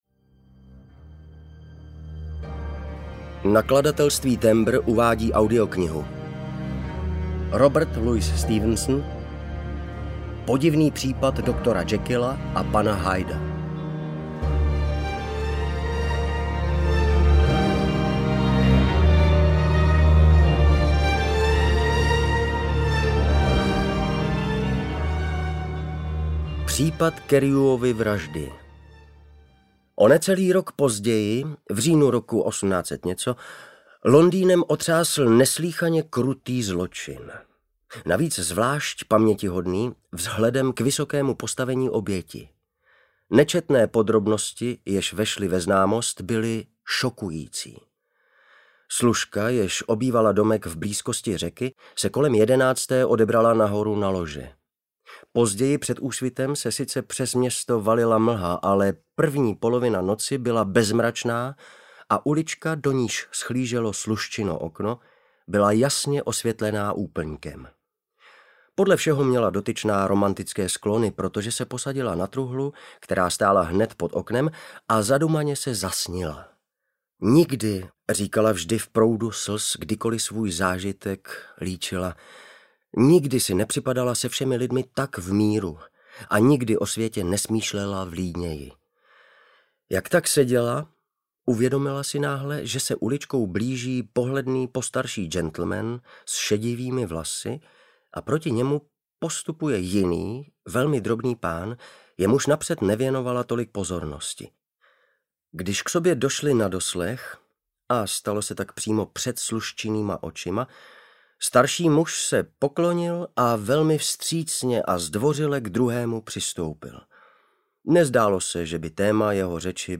Audio knihaPodivný případ doktora Jekylla a pana Hyda
Ukázka z knihy